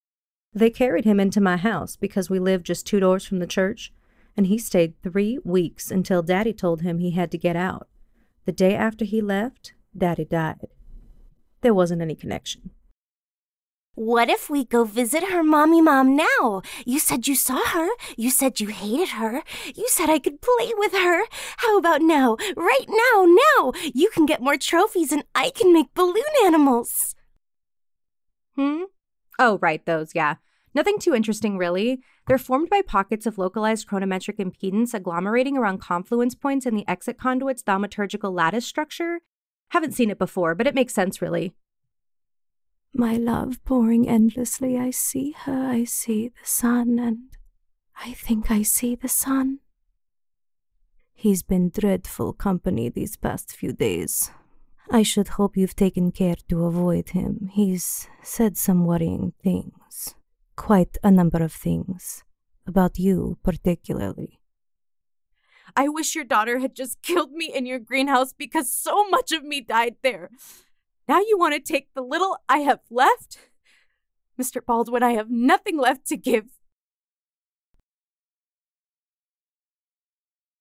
Female
My voice is clear, articulate, and genuine. My natural speaking voice is medium toned with a neutral American accent.
Television Spots
Commercial Demo
All our voice actors have professional broadcast quality recording studios.